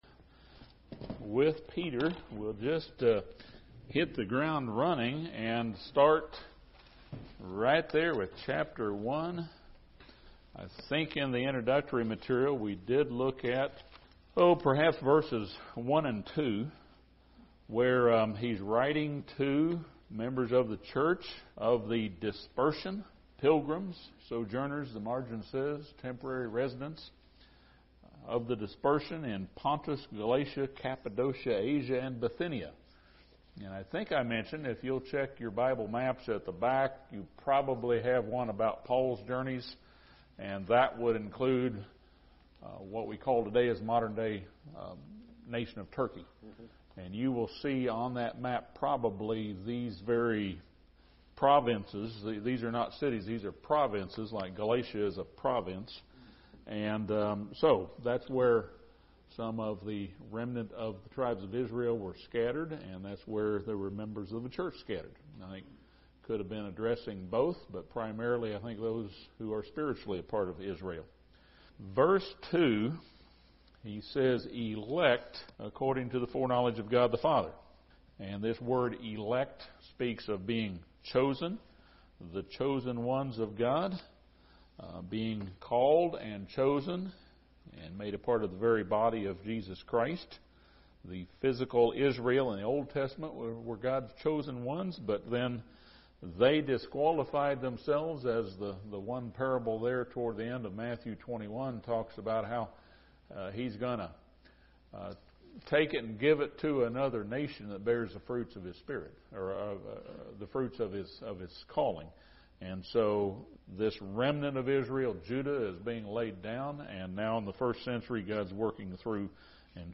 This Bible study surveys instructions from 1 Peter chapters 1 and 2.